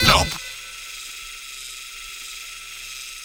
sentry_scan.wav